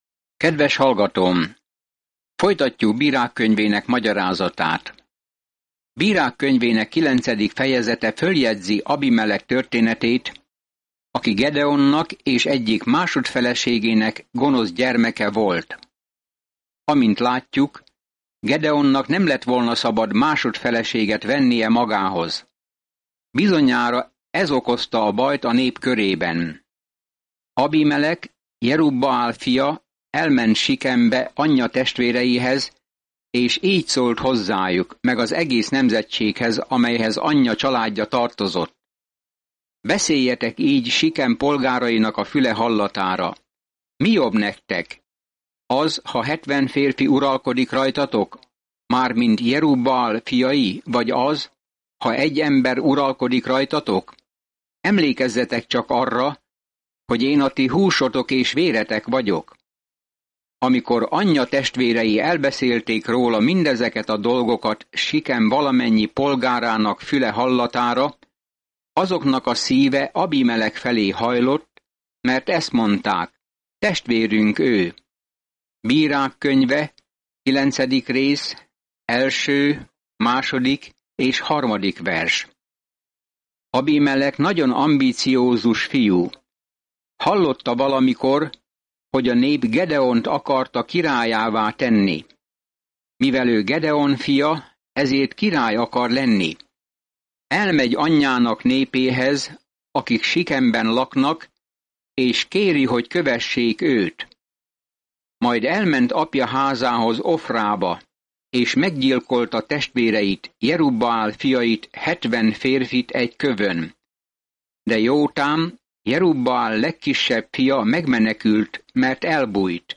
Szentírás Bírák 9 Bírák 10 Nap 6 Olvasóterv elkezdése Nap 8 A tervről A Judges feljegyzi azoknak az embereknek olykor fordulatos, fejjel lefelé forduló életét, akik új életüket Izraelben rendezik be. Napi utazás a Bírákon keresztül, miközben hallgatod a hangos tanulmányt, és olvasol válogatott verseket Isten szavából.